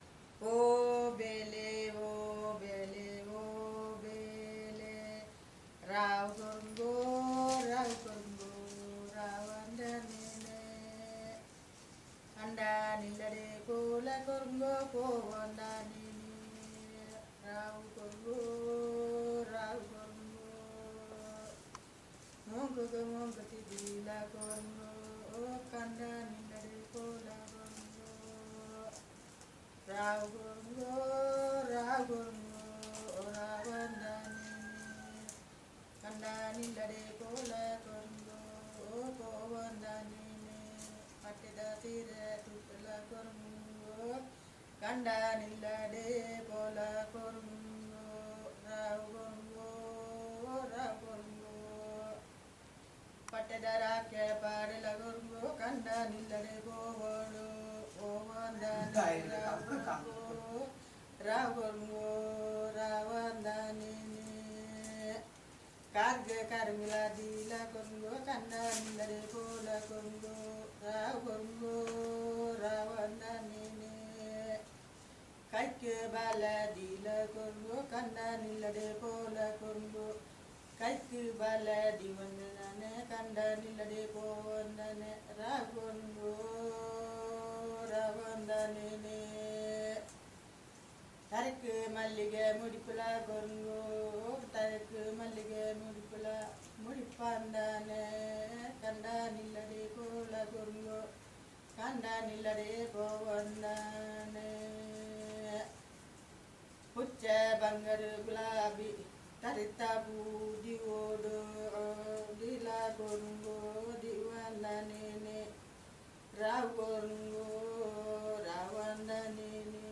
Performance of a Folk song